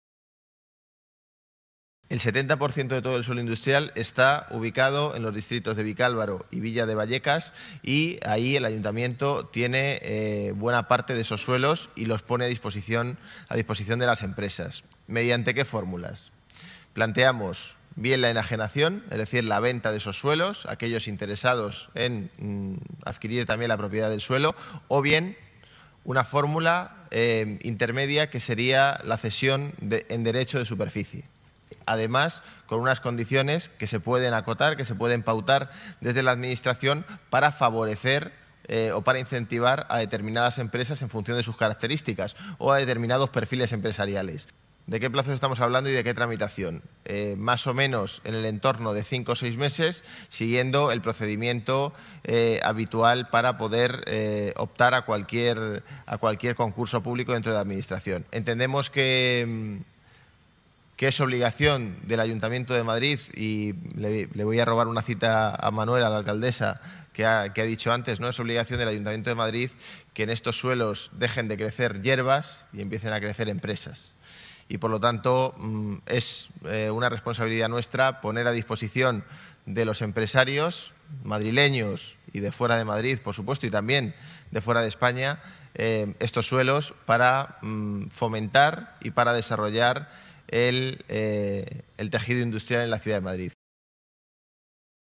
Presentación estrategia
José Manuel Calvo habla sobre la ubicación del suelo industrial de Madrid